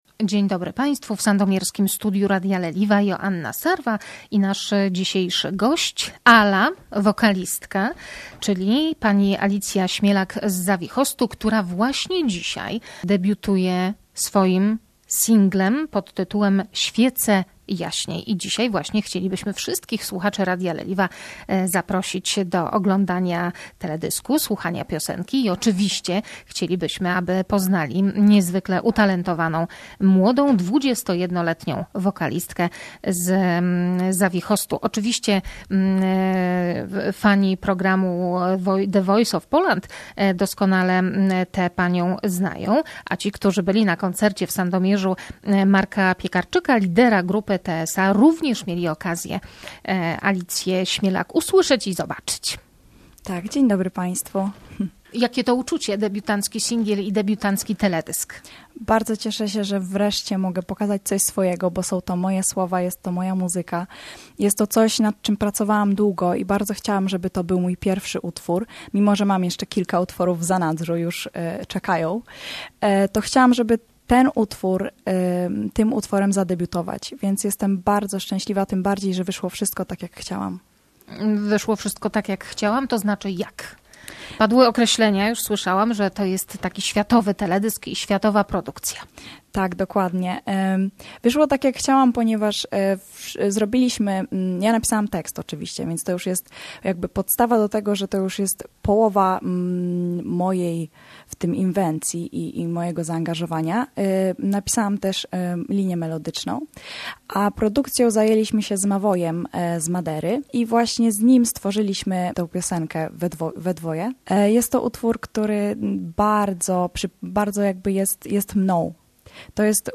podczas swojej wizyty w sandomierskim studiu Radia Leliwa